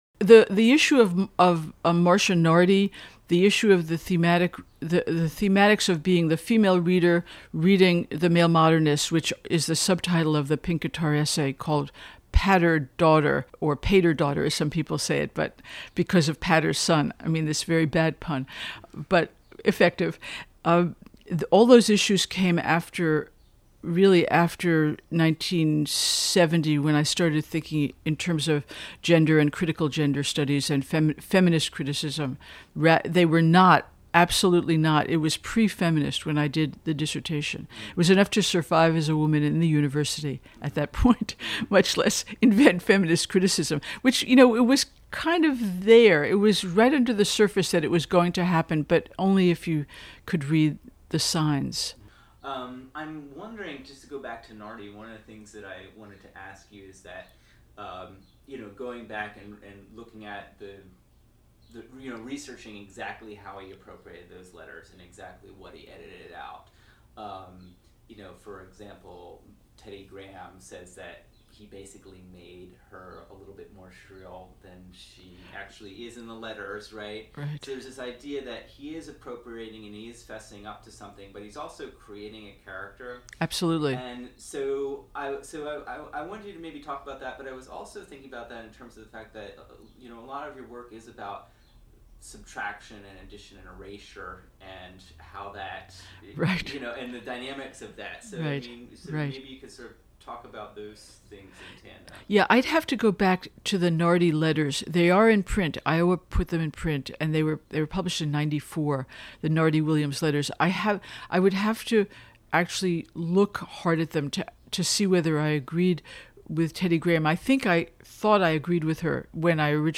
It has always been a question for me whether any consolidation is worthwhile or advisable, which is why interviews like those below have been kept in as close to their rawest form (with some mild editing) because I have felt it is better to make them available during the process, rather than to wait and wait for an ideal form that never comes.